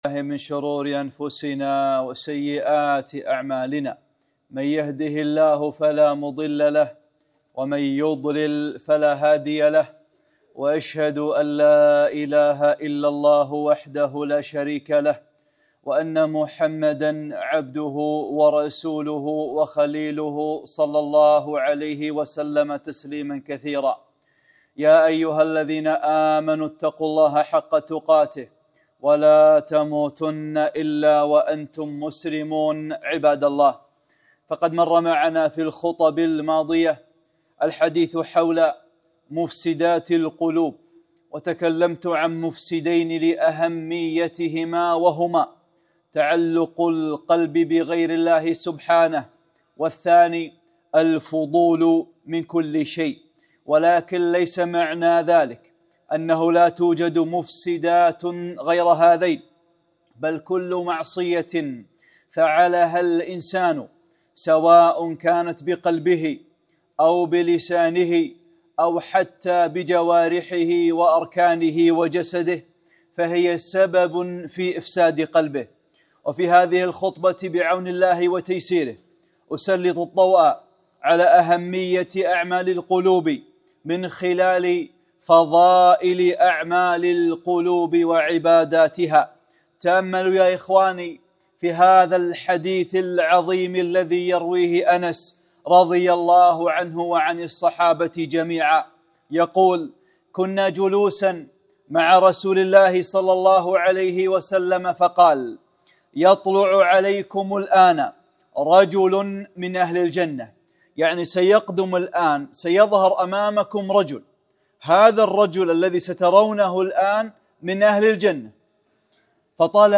خطبة - (8) تطهير القلب باتجاه المسلمين | أعمال القلوب